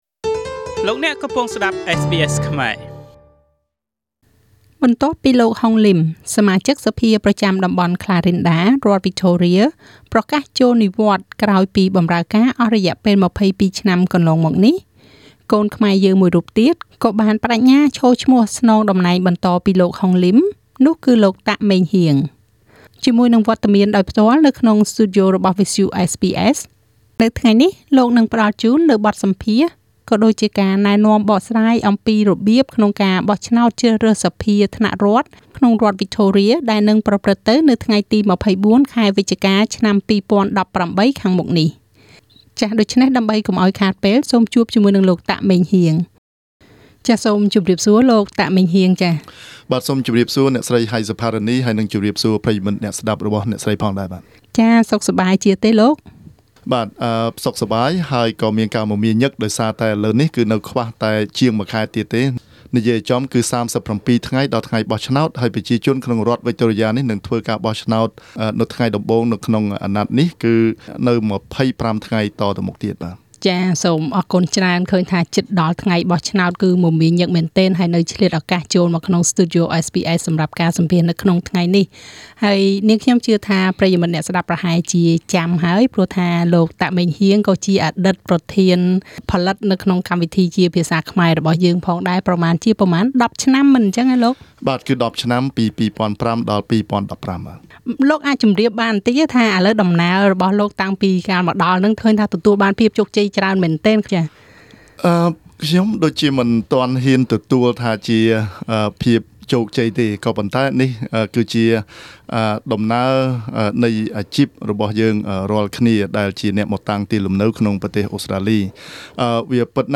នៅក្នុងបន្ទប់ផ្សាយរបស់វិទ្យុ SBS បេក្ខជនតំណាងរាស្រ្តមកពីបក្សលេប័ររូបនេះ ពន្យល់ពីរបៀបបោះឆ្នោតត្រឹមត្រូវ ចៀសវាងសន្លឹកឆ្នោតមិនបានការ។